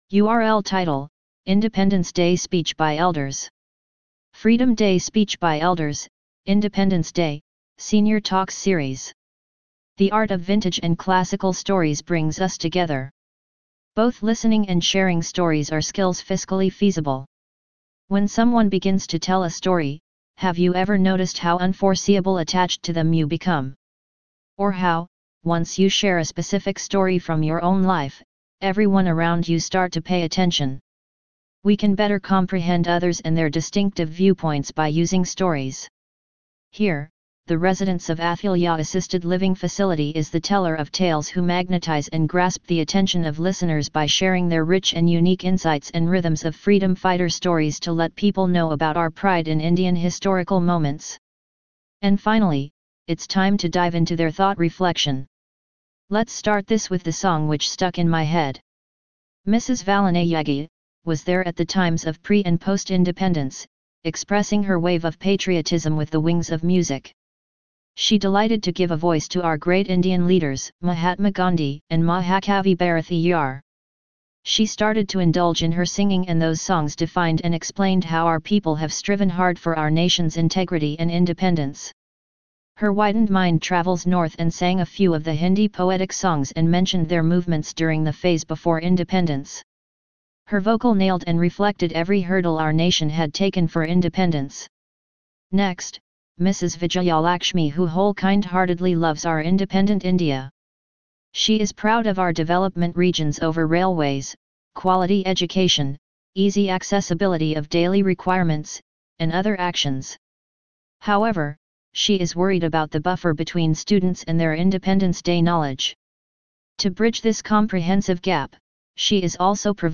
Freedom Day speech by elders (Independence Day) – Senior talks series
Freedom-day-speech-by-elders-Independence-day-Senior-talks-series.mp3